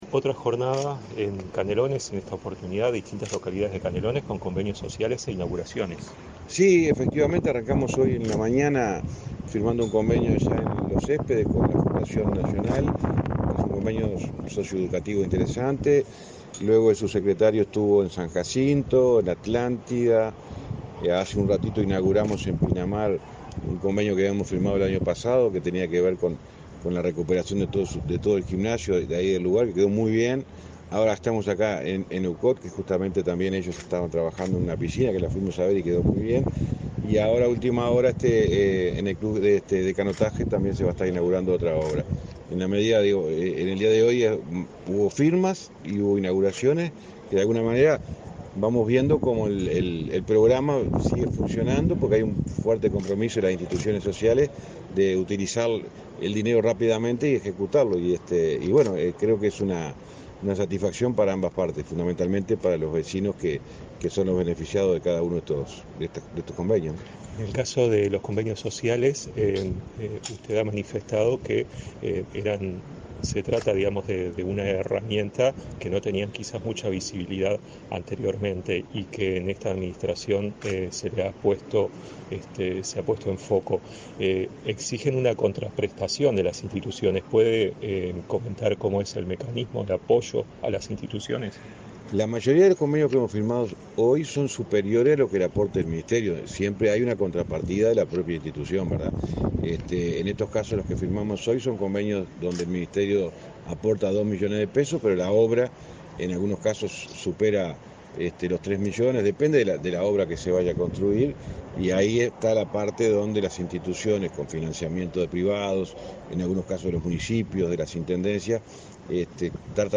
Declaraciones a la prensa de ministro del MTOP, José Luis Falero
Declaraciones a la prensa de ministro del MTOP, José Luis Falero 05/05/2023 Compartir Facebook X Copiar enlace WhatsApp LinkedIn El Ministerio de Transporte y Obras Públicas (MTOP) inauguró, este 5 de mayo, obras realizadas por convenios sociales y firmó nuevos acuerdos con instituciones de Montevideo y Canelones. Tras la recorrida, el ministro José Luis Falero realizó declaraciones a la prensa.